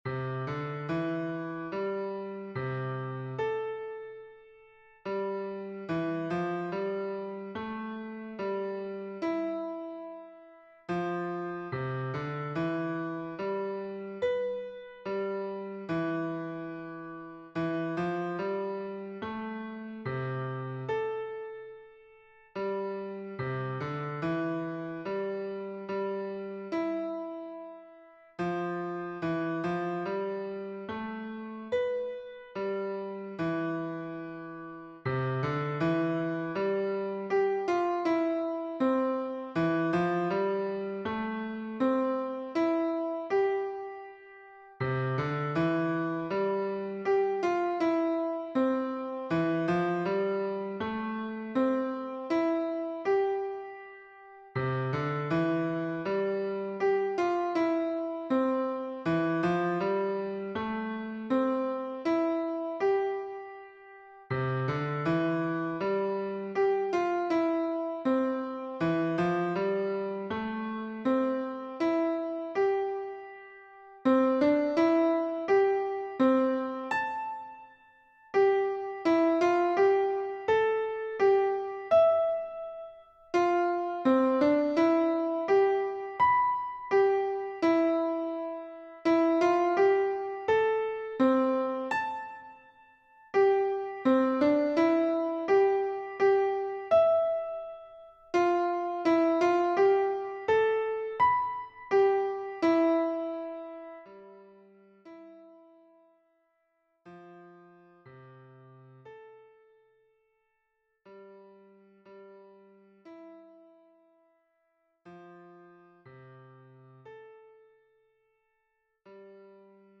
(Solo Cello)